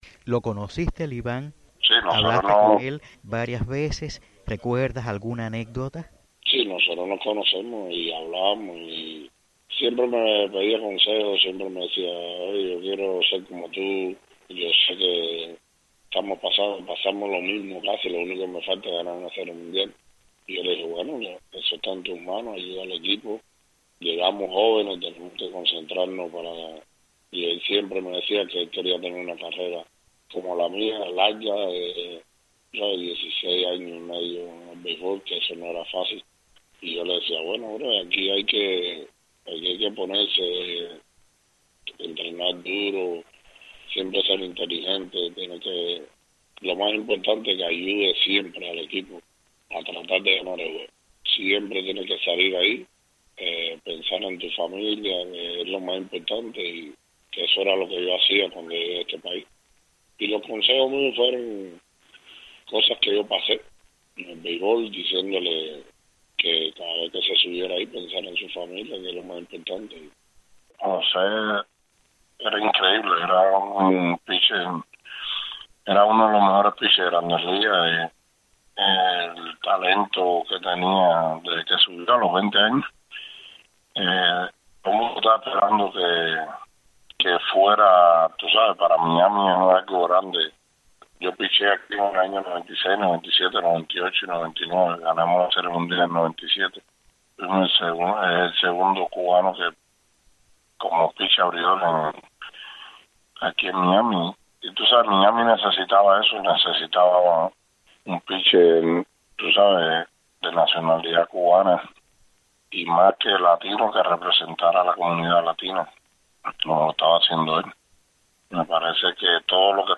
El exlanzador de Grandes Ligas Liván Hernández (Villa Clara, 1975) dijo a Martí Noticias que José siempre le decía que quería ser como él, y "tener una carrera como la mía, larga, en el béisbol, que es muy difícil llegar ahí, y yo le decía, bueno brother, aquí hay que ponerse a entrenar duro, siempre ser inteligente, y lo más importante, que ayudes siempre al equipo a tratar de ganar el juego y pensar en tu familia".